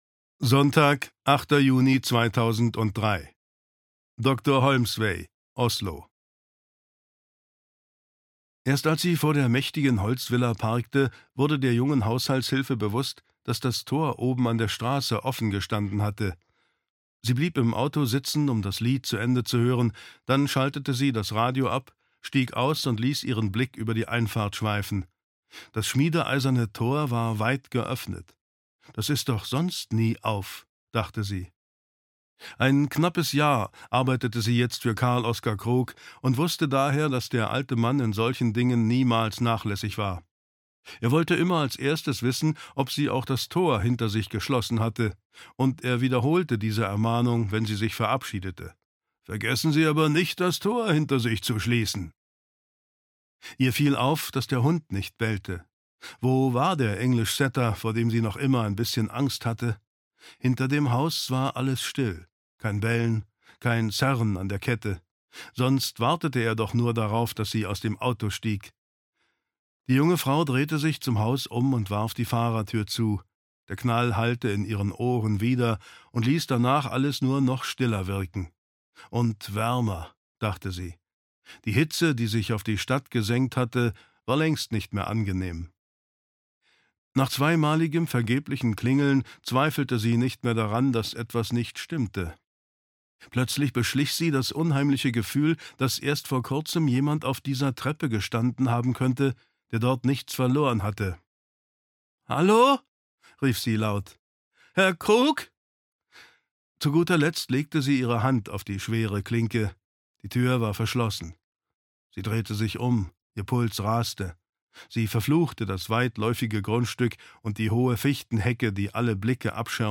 Der letzte Pilger (Ein Fall für Tommy Bergmann 1) - Gard Sveen - Hörbuch